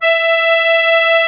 MUSETTE 1.12.wav